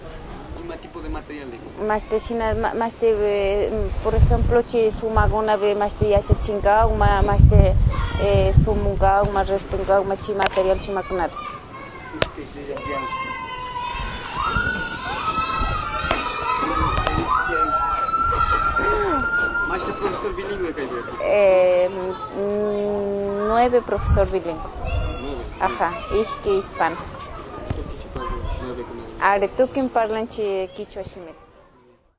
Entrevistas - Santa Cruz